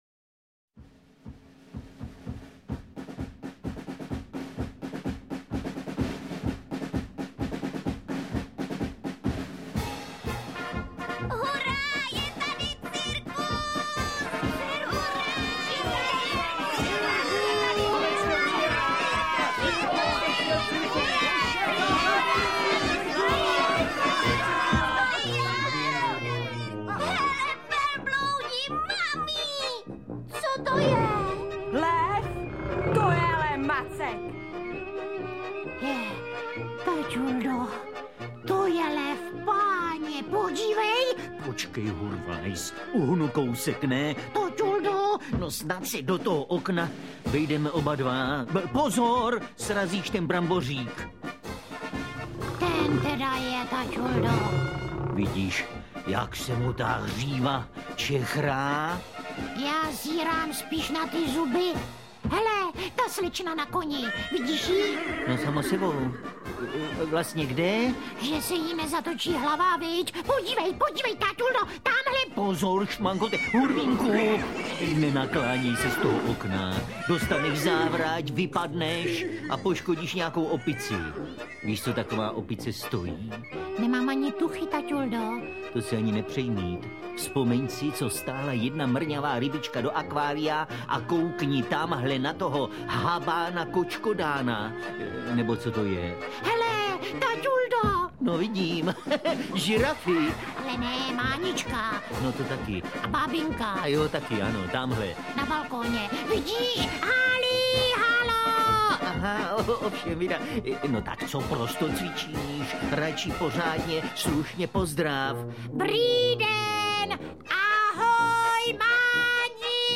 Interpret:  Miloš Kirschner
Oblíbená a úspěšná řada archivních nahrávek Divadla Spejbla a Hurvínka s Milošem Kirschnerem, dlouholetým interpretem obou dřevěných protagonistů a ředitelem tohoto divadla, pokračuje už čtrnáctým titulem.